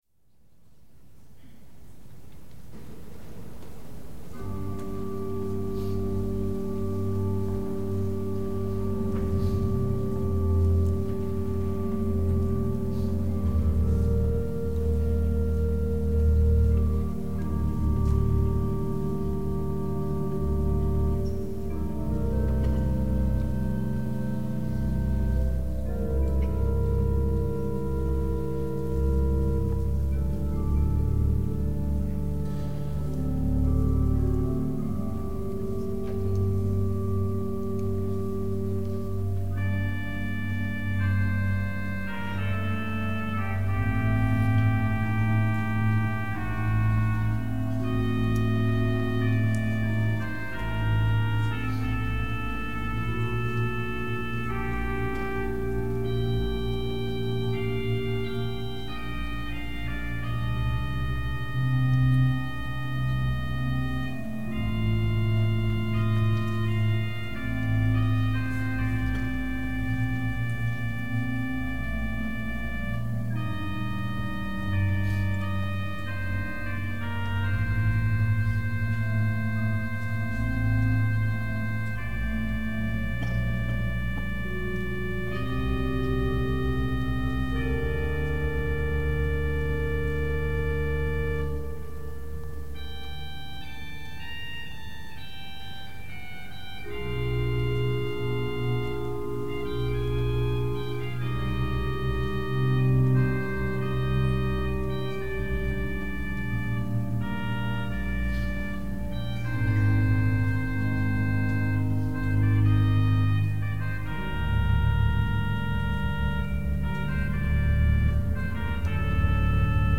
interpretado en el �rgano de la Ep�stola